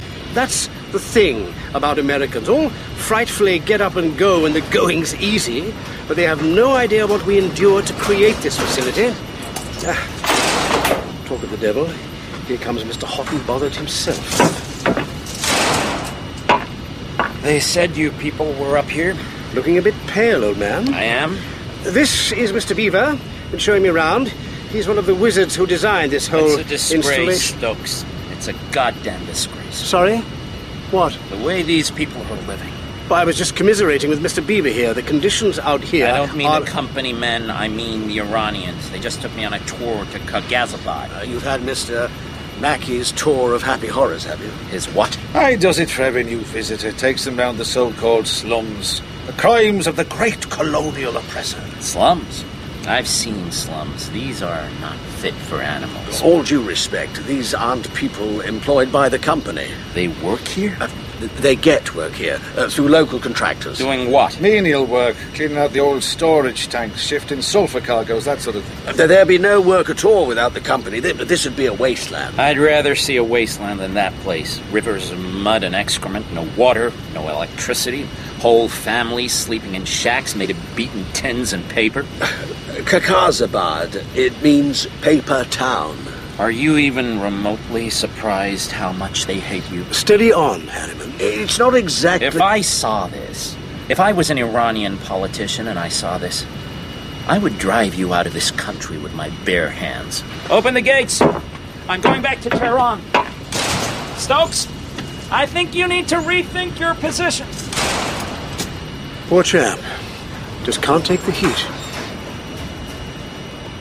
The following discussion between the American negotiator and the British representatives will clue you into the heart of the matter: